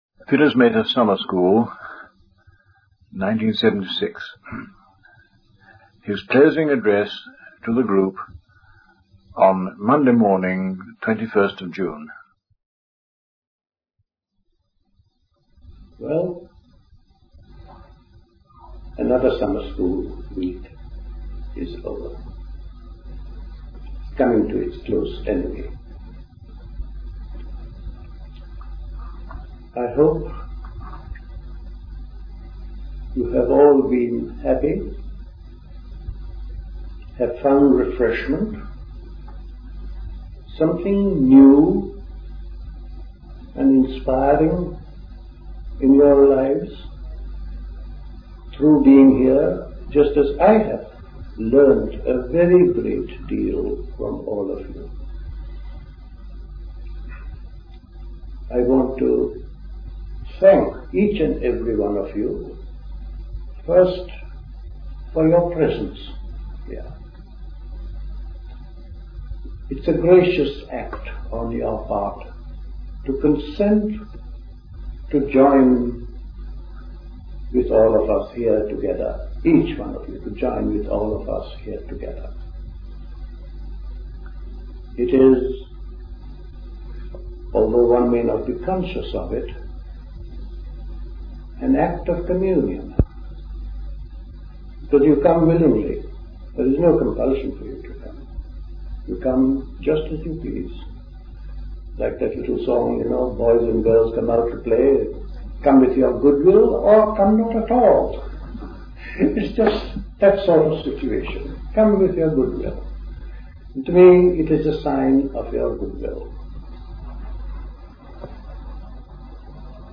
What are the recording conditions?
Recorded at the 1976 Park Place Summer School. This was the closing address.